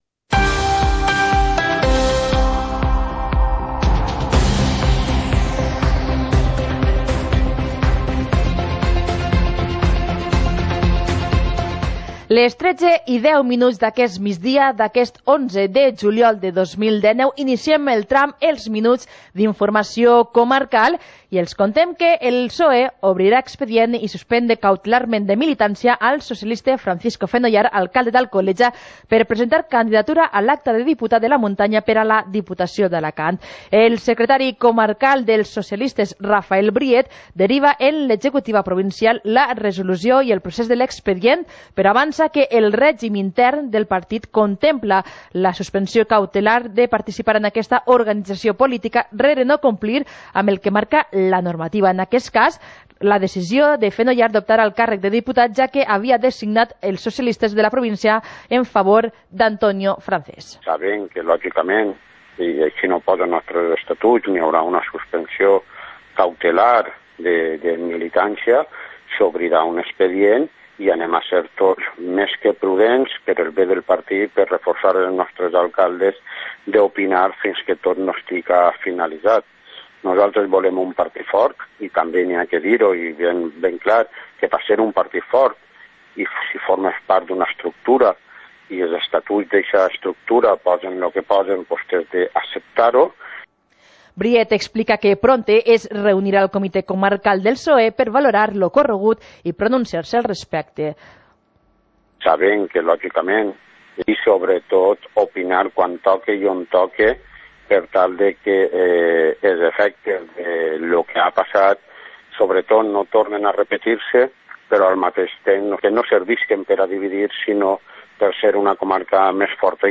Informativo comarcal - jueves, 11 de julio de 2019